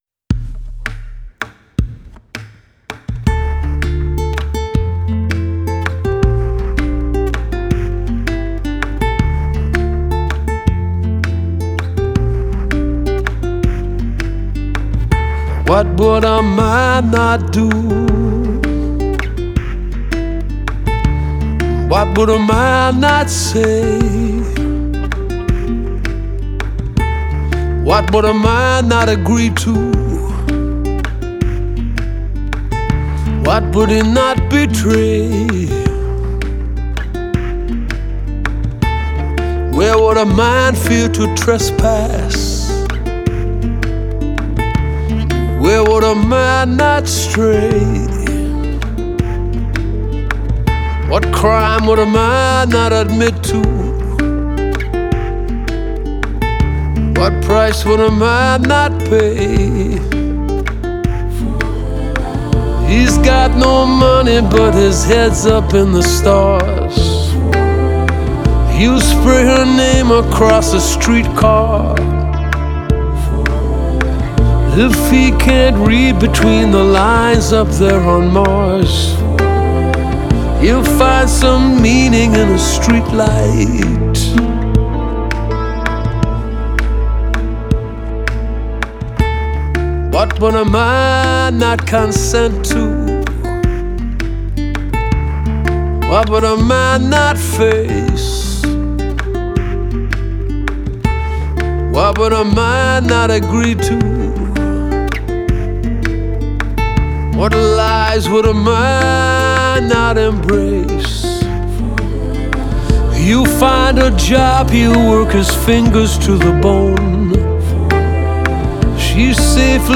pop rock soft rock Folk rock